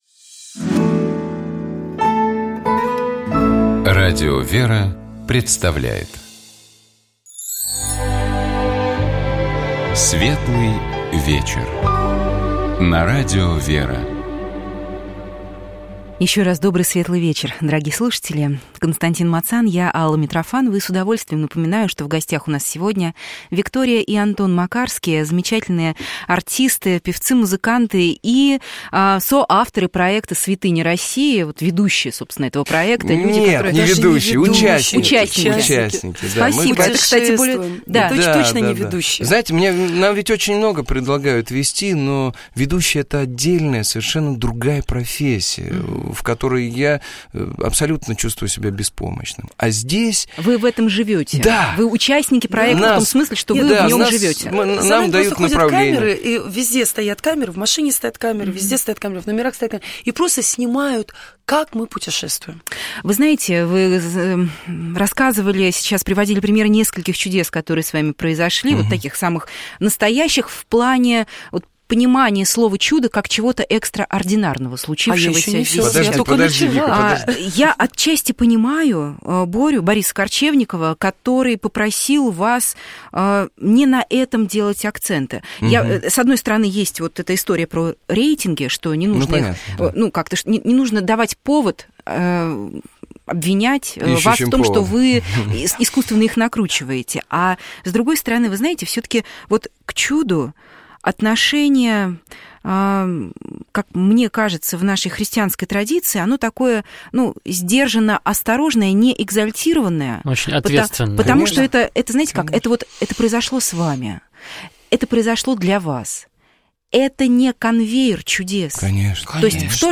У нас в гостях были известные актеры и музыканты Антон и Виктория Макарские.